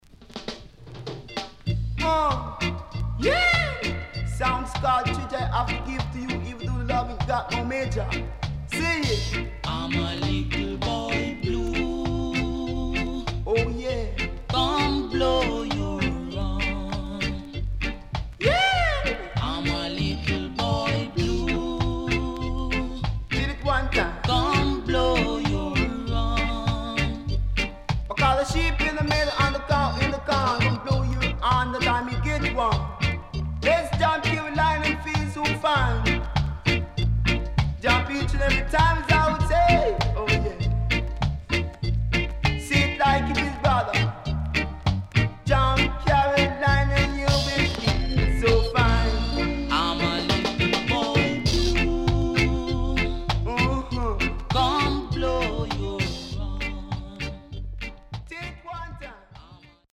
HOME > REGGAE / ROOTS  >  70’s DEEJAY
Deejay Cut
SIDE A:少しチリノイズ入りますが良好です。